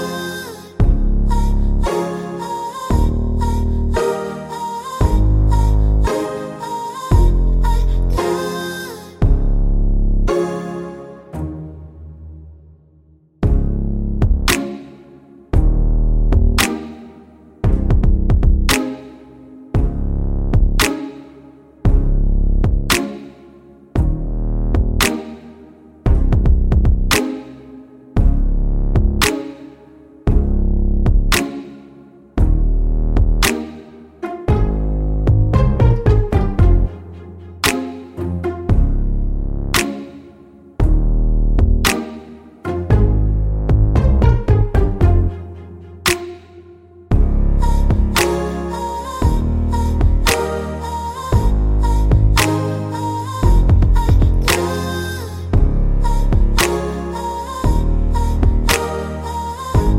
no Backing Vocals at all Pop (2010s) 2:57 Buy £1.50